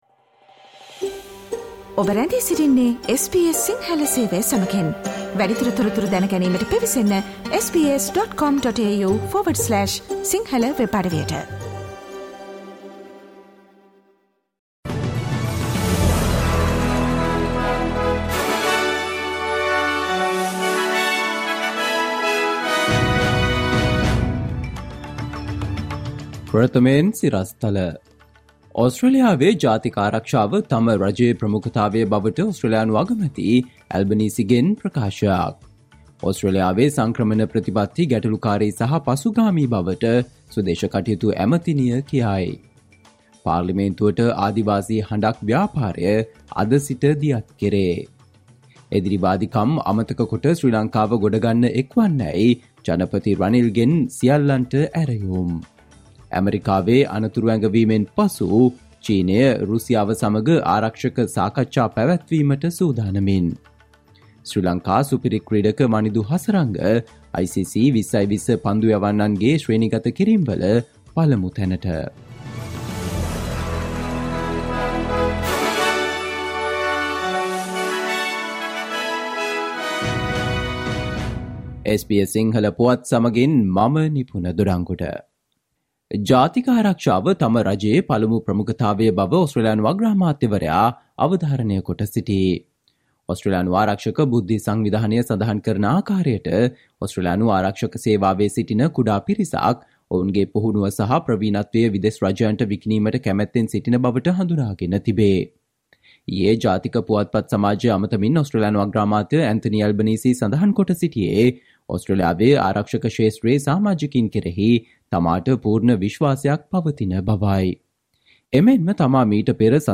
ඕස්ට්‍රේලියාවේ නවතම පුවත් රැගත් SBS සිංහල සේවයේ 2023 පෙබරවාරි 23 වන දා බ්‍රහස්පතින්දා වැඩසටහනේ ප්‍රවෘත්ති ප්‍රකාශයට සවන් දෙන්න.